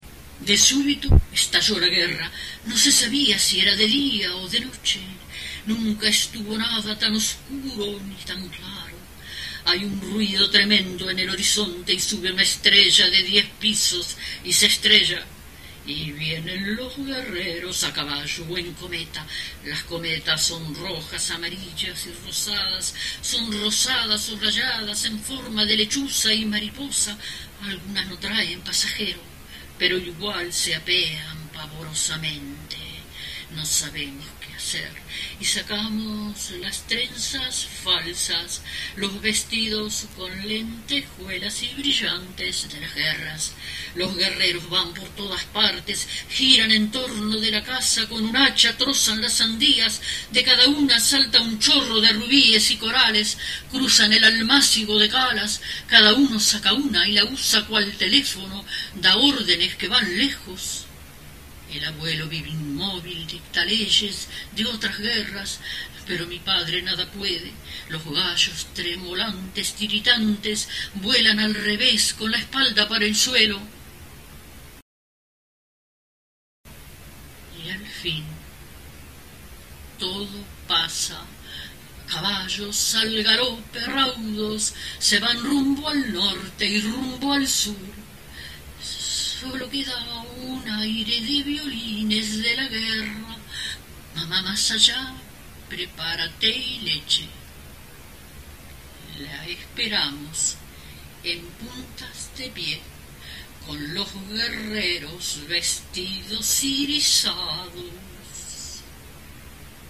La grabación es del cassette Diadema, realizado en el Estudio Naif, de Montevideo, y editado en 1994 en su Serie de la Palabra; el cassette recoge el recital poético que Marosa Di Giorgio comenzó a realizar a mediados de los 80.